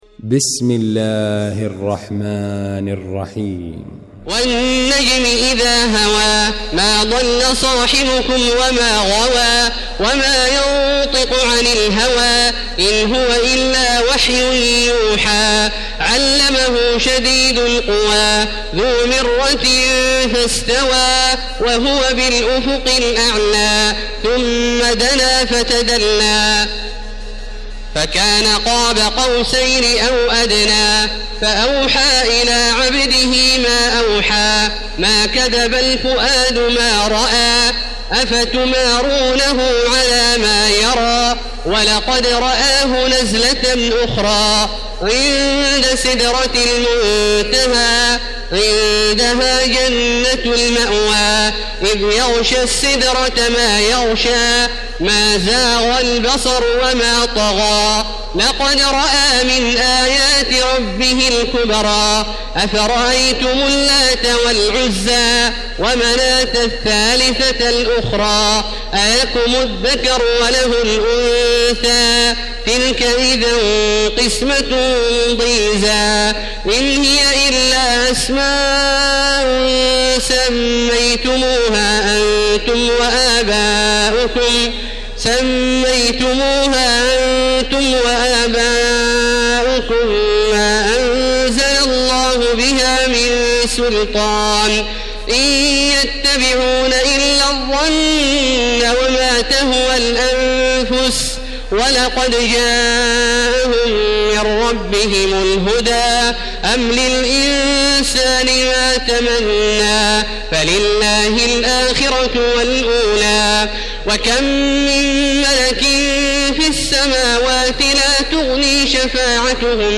المكان: المسجد الحرام الشيخ: فضيلة الشيخ عبدالله الجهني فضيلة الشيخ عبدالله الجهني النجم